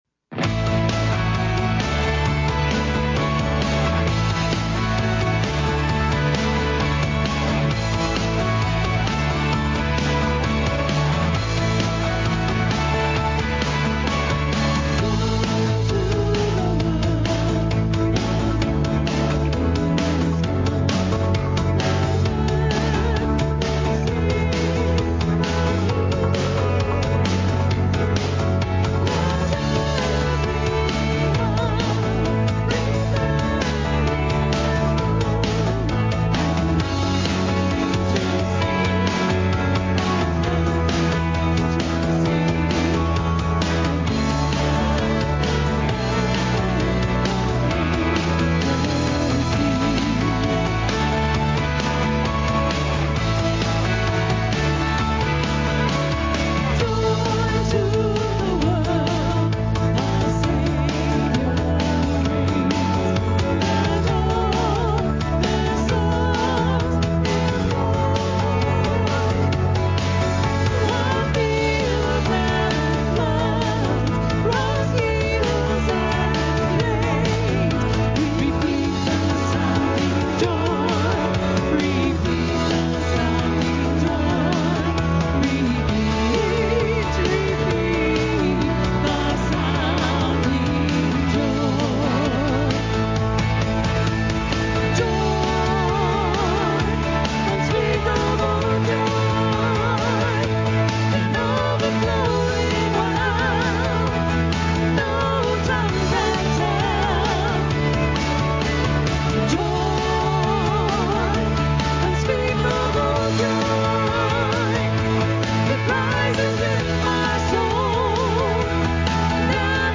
Praise Team Audio